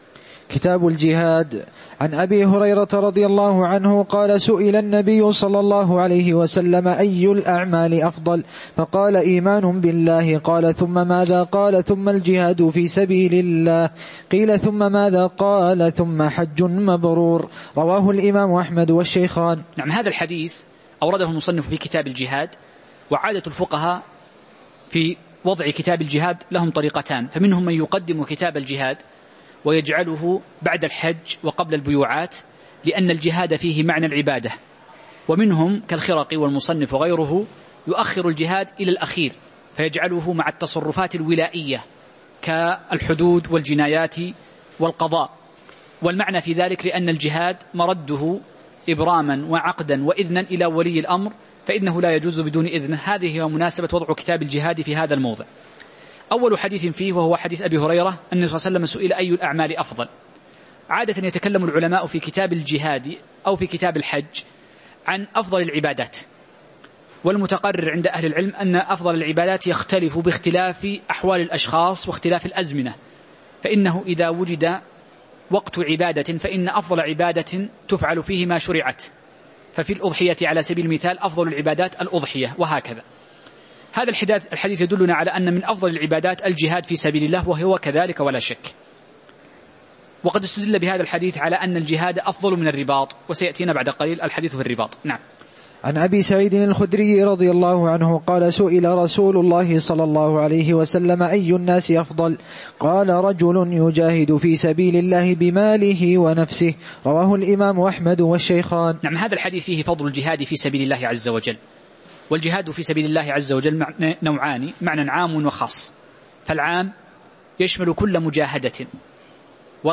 تاريخ النشر ١٢ ربيع الأول ١٤٤١ هـ المكان: المسجد النبوي الشيخ